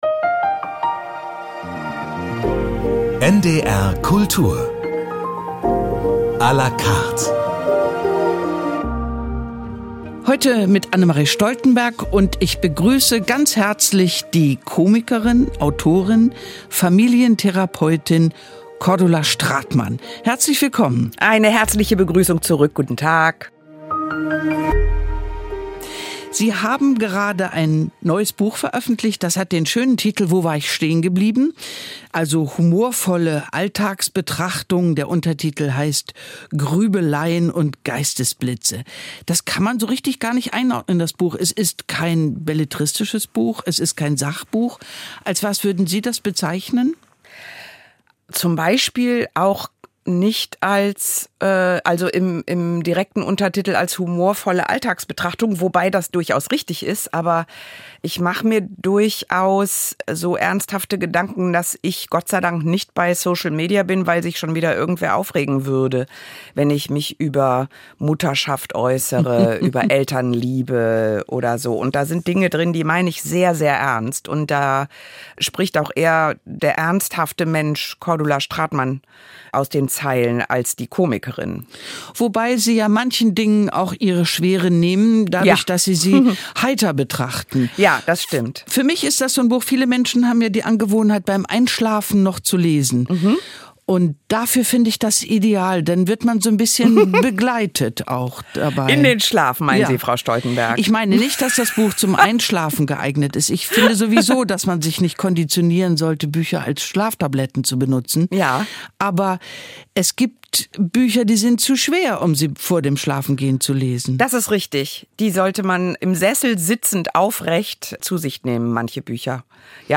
Requiem aeternam (Sopran, Chor) (27:50) Requiem d-Moll KV 626 - Dies irae (Chor) (37:20) Violoncellosonate g-Moll, op. 65 - Largo (3.